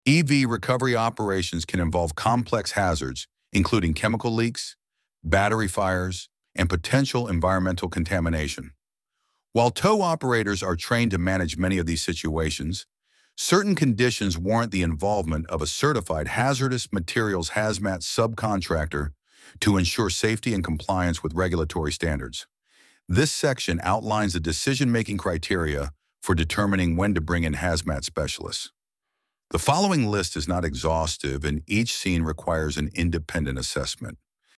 ElevenLabs_Topic_1.4.6.mp3